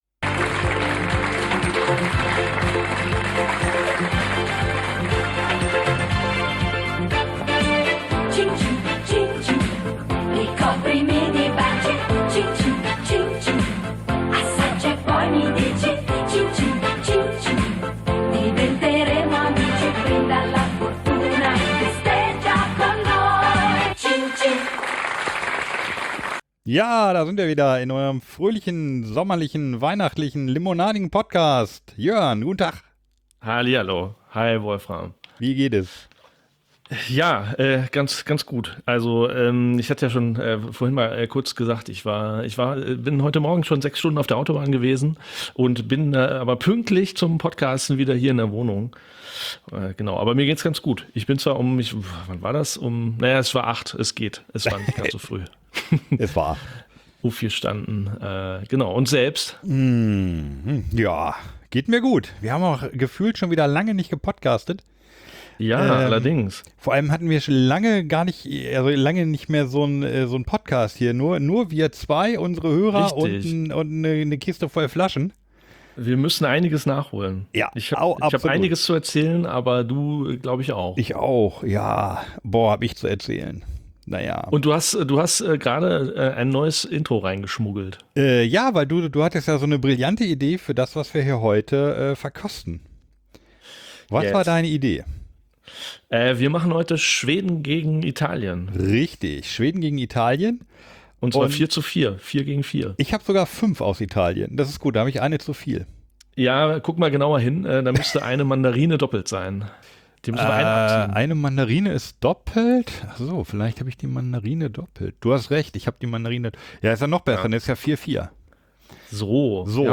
Wir treffen uns live zum Super-Bowl und trinken Keine Limo.